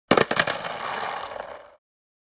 Our Skateboard Sound Effects include everything from Skating Movements and handling to Stunts, Tricks, and Wheels.
Skateboarding-falling-on-the-ground-3.mp3